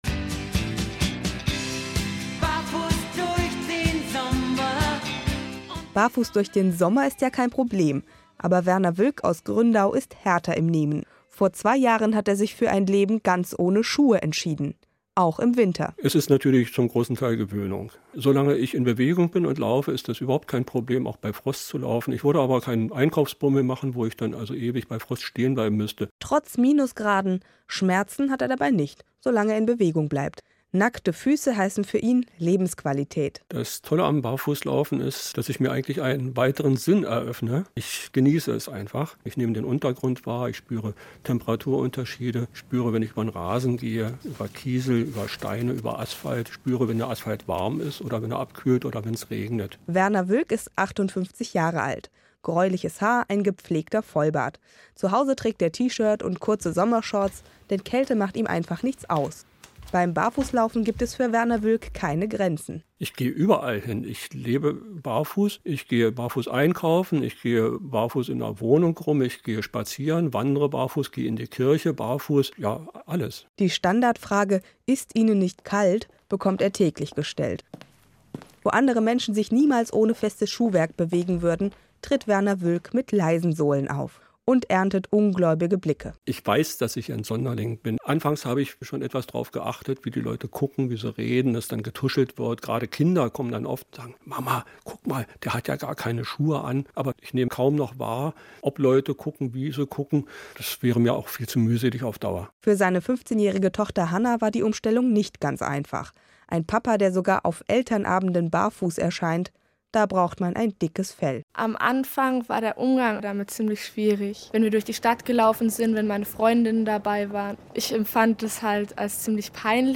Rundfunkinterview eines Barfu�gehers